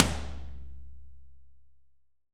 Index of /90_sSampleCDs/AKAI S6000 CD-ROM - Volume 3/Drum_Kit/AMBIENCE_KIT3
R.AMBTOML2-S.WAV